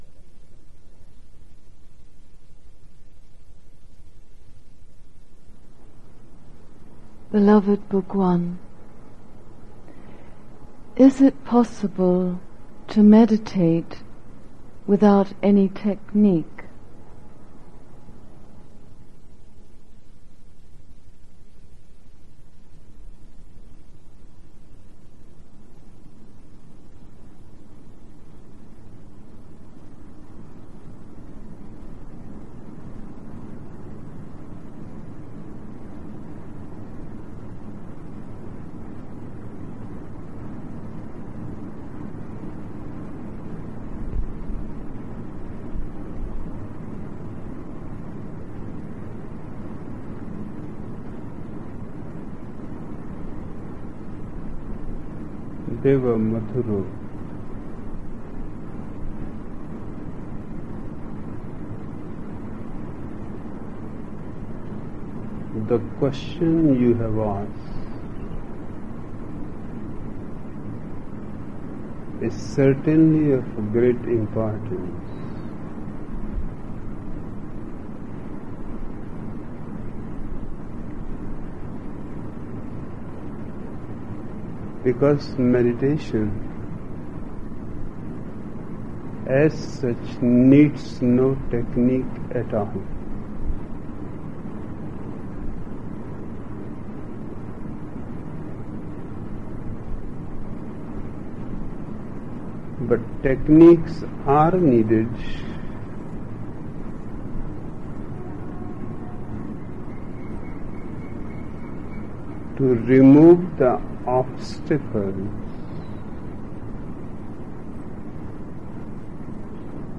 Each program has two parts, Listening Meditation (Osho discourse) and Satsang Meditation.
Throughout all of these discourses is a common thread and that is Osho’s teaching of witnessing. The Listening Meditations in the following programs include discourses given by Osho from 1974 – 1988 and were given in Pune, India; Rajneeeshpuram, OR; Kathmandu, Nepal; Punte del Este, Uruguay; Mumbai and Pune, India.